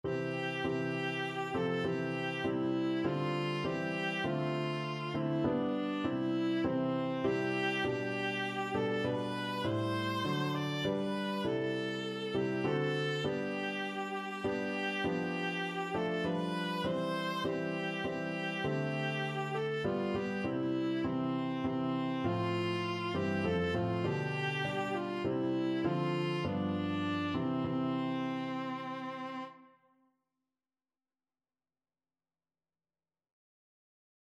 Viola
3/4 (View more 3/4 Music)
C major (Sounding Pitch) (View more C major Music for Viola )
Traditional (View more Traditional Viola Music)